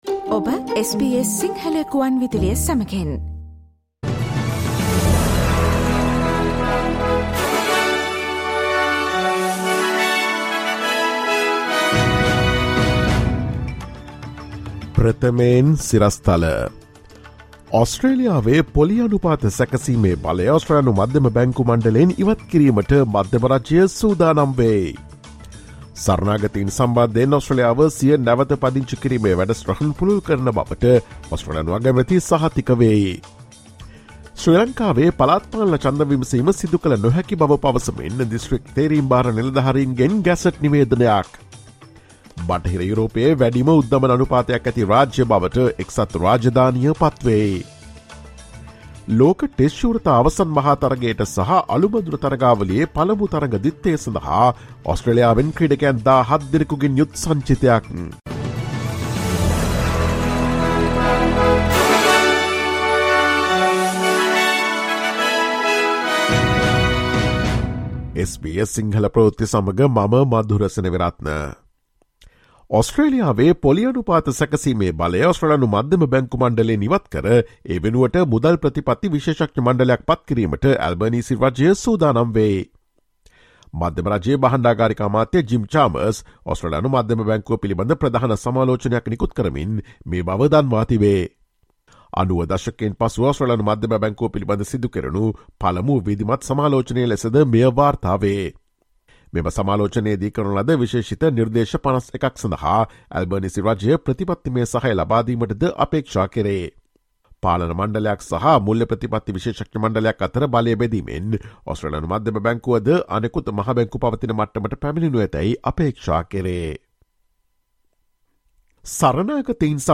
Listen to the latest news from Australia, Sri Lanka, and across the globe, and the latest news from the sports world on SBS Sinhala radio news – Thursday, 20 April 2023.